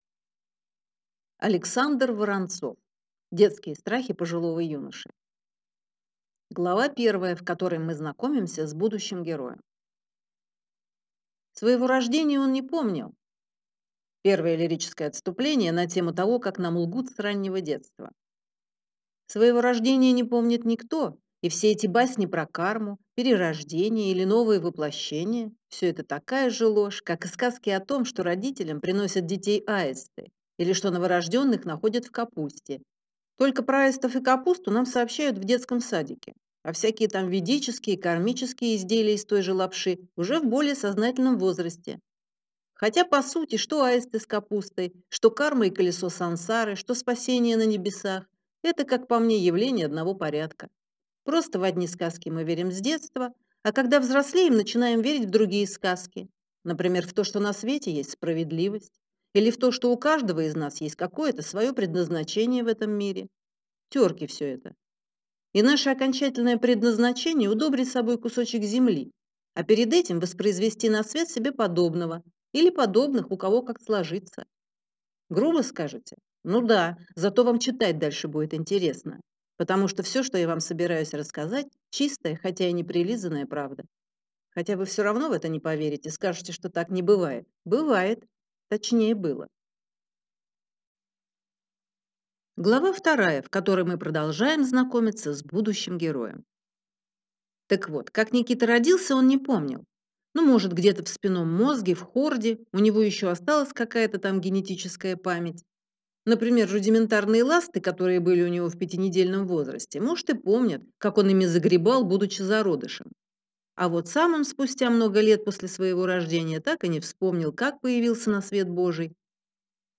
Аудиокнига Детские страхи пожилого юноши | Библиотека аудиокниг
Прослушать и бесплатно скачать фрагмент аудиокниги